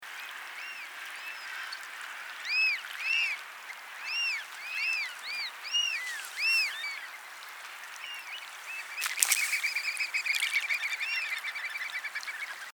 Voice
Oystercatchers are very noisy birds; they make a shrill wheep or kleep, as well as loud pic pic pics.
american-oystercatcher-call.mp3